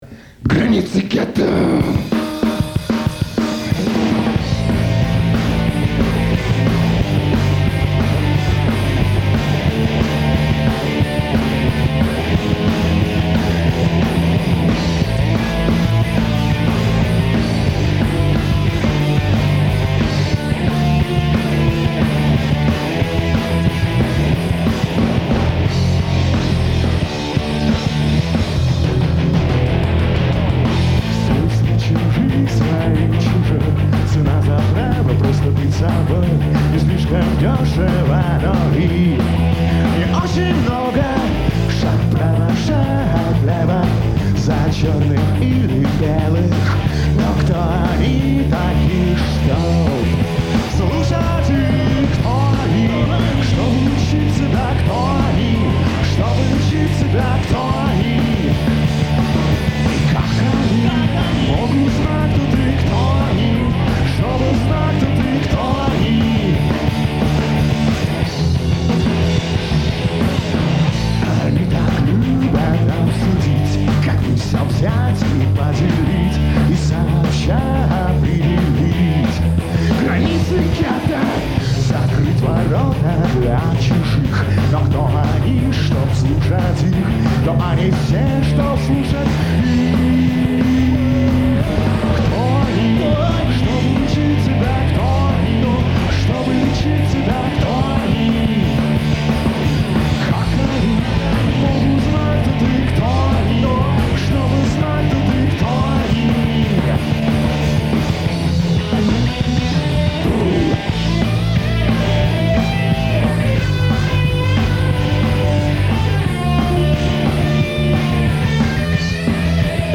Нашёл и оцифровал кассету